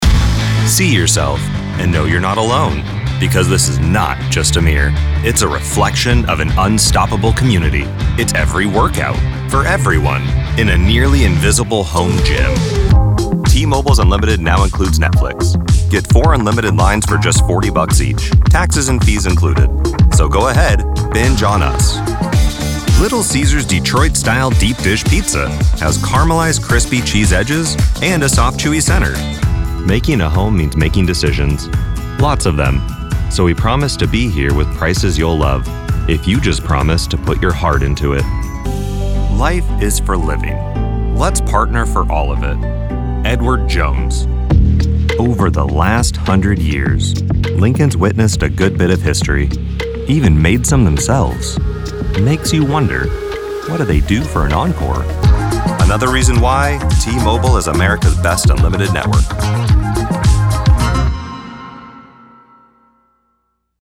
Home recording studio ready with high quality sound. Deep male voice with a clear diction and annunciation.
Commercial Demo